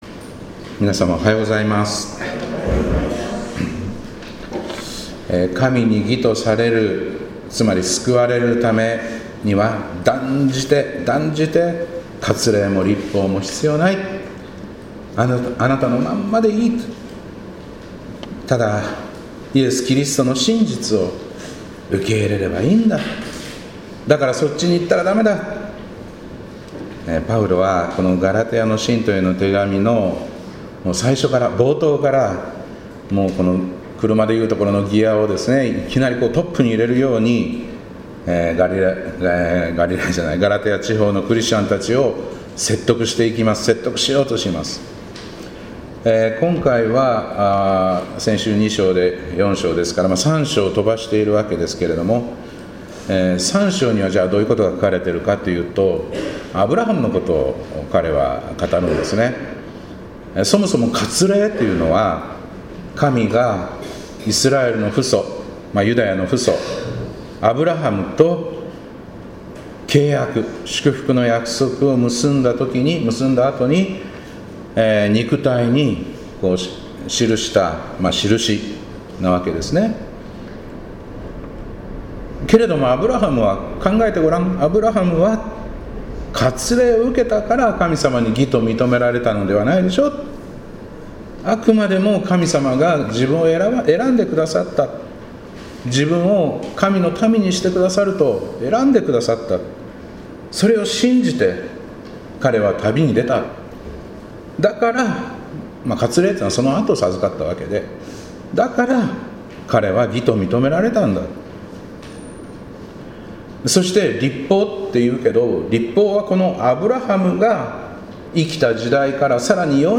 2025年5月18日礼拝「あの素晴らしい愛をもう一度」